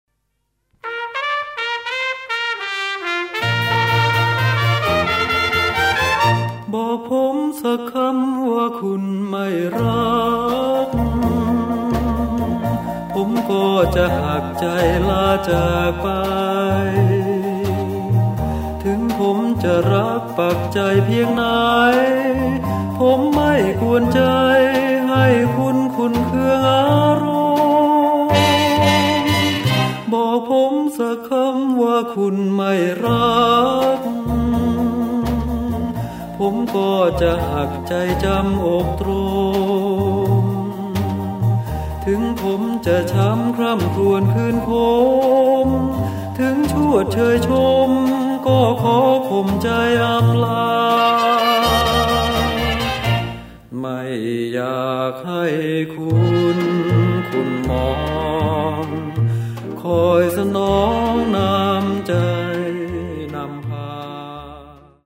สินค้า / เพลงลูกกรุง ฟังสบายๆ คลายร้อน